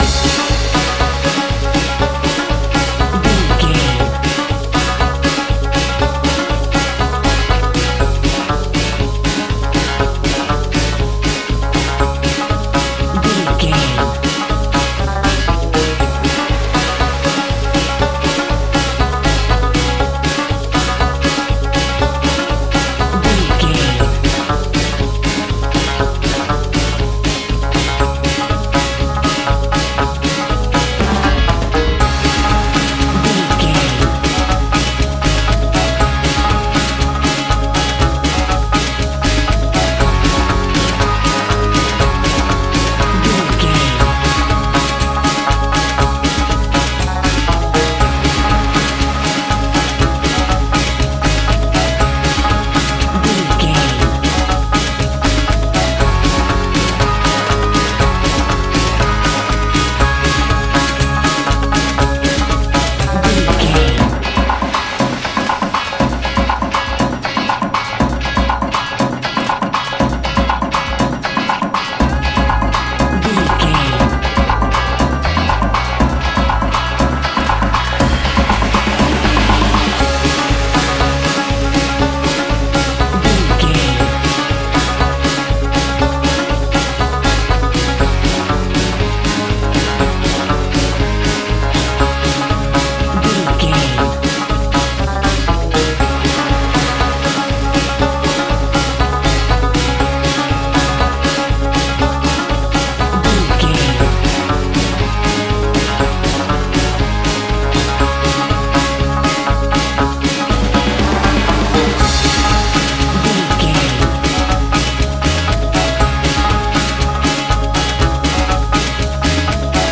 Ionian/Major
Fast
hypnotic
energetic
bouncy
electric guitar
drums